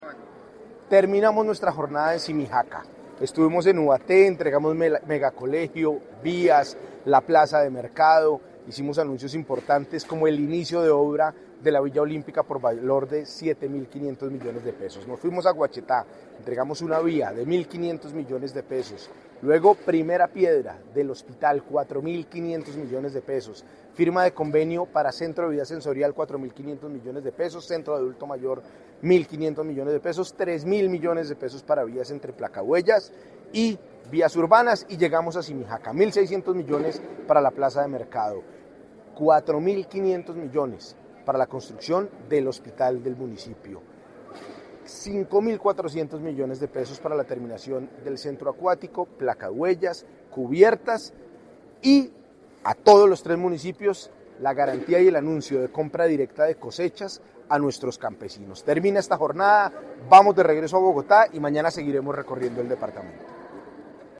Declaración de Nicolás García Bustos, gobernador de Cundinamarca.